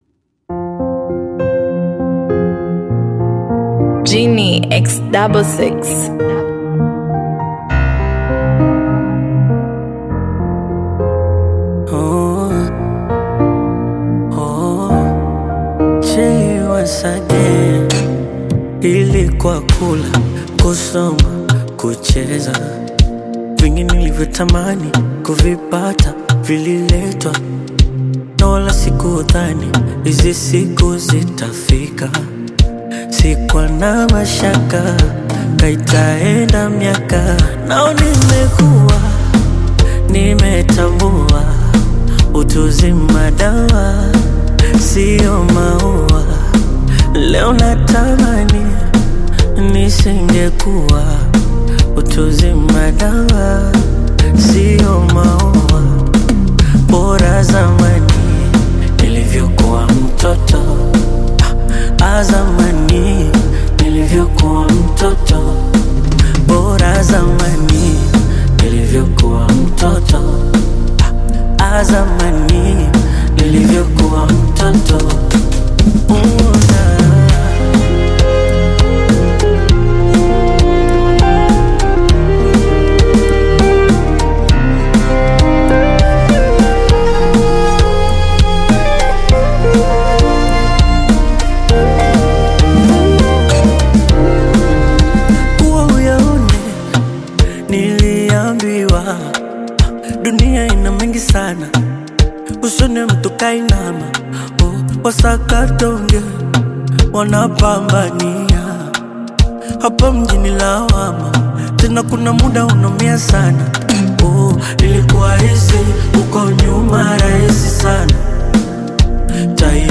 reflective Bongo Flava single
Genre: Bongo Flava